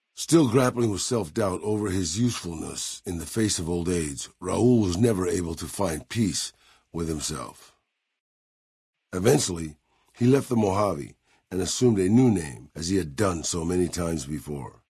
Category:Fallout: New Vegas endgame narrations Du kannst diese Datei nicht überschreiben.